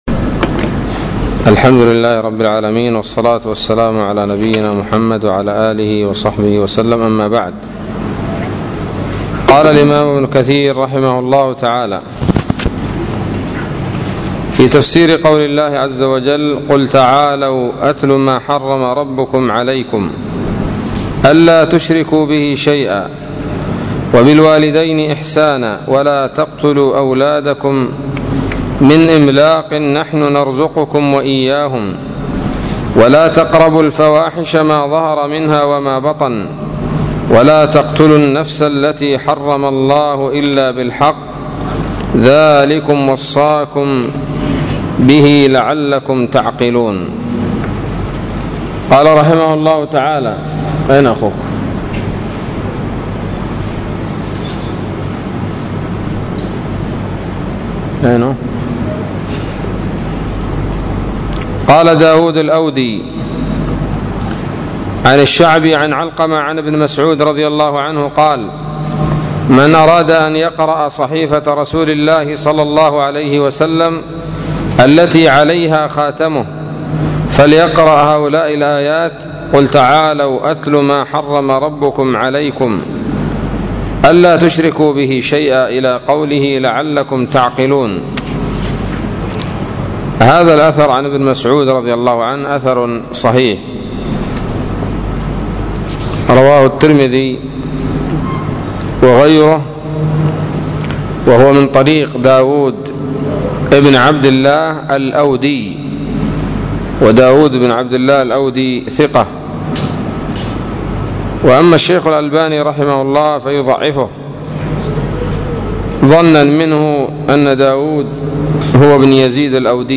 الدرس الرابع والستون من سورة الأنعام من تفسير ابن كثير رحمه الله تعالى